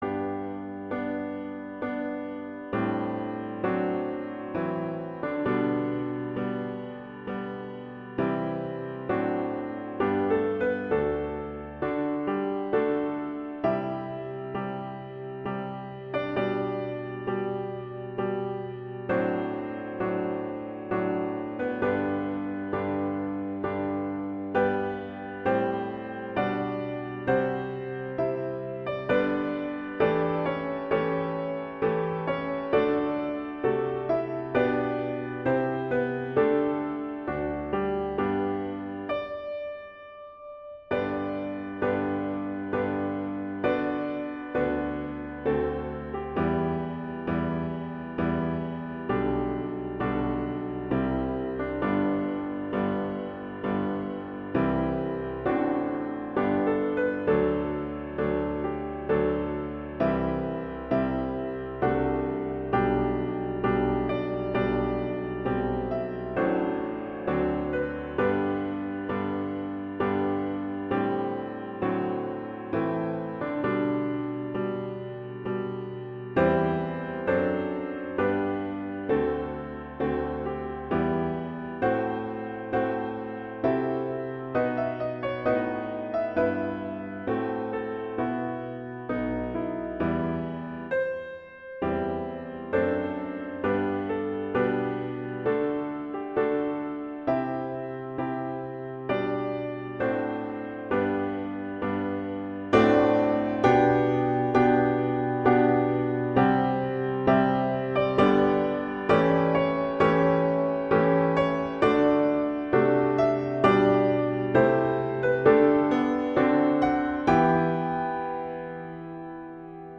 classical, wedding, traditional, easter, festival, love
G major
♩=66 BPM